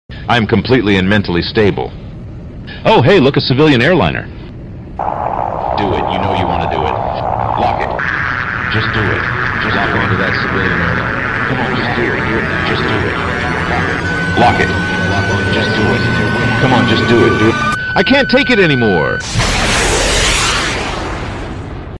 The Missile Guidance System freaks sound effects free download